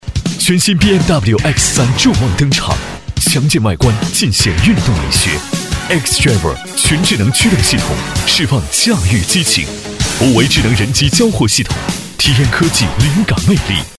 激情力度 品牌广告